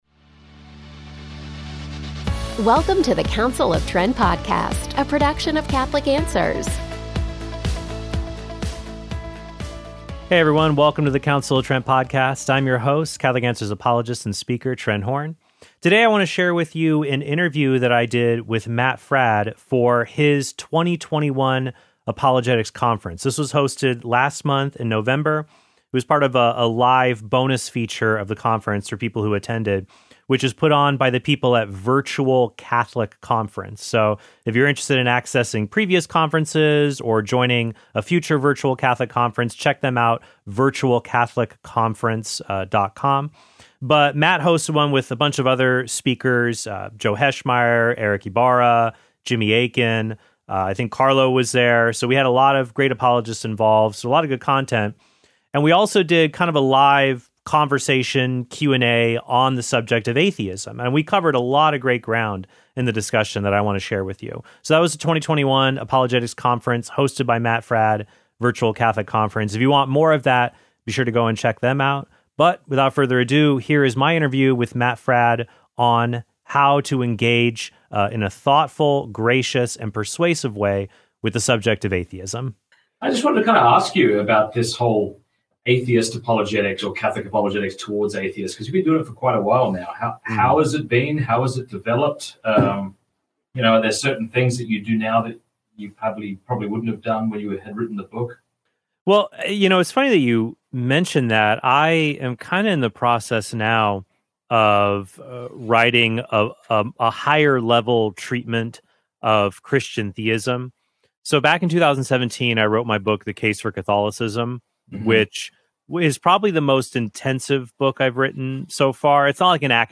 It was part of a live bonus feature of the conference for people who attended, which is put on by the people at Virtual Catholic Conference.
And we also did kind of a live conversation Q&A on the subject of atheism.